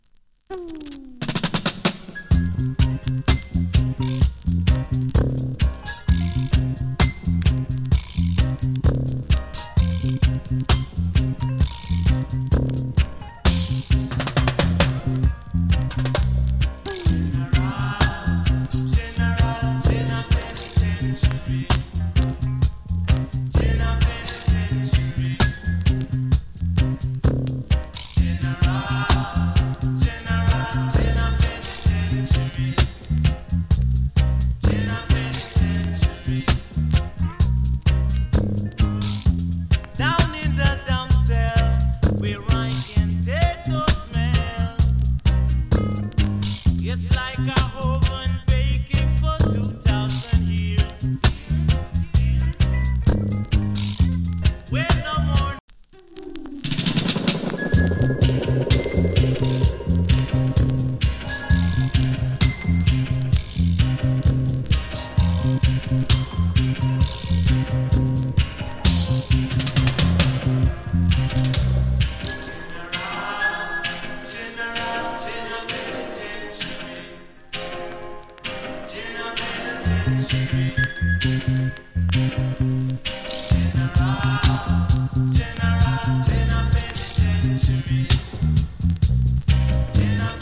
チリ、パチノイズ少々有り。
KILLER ROOTS VOCAL !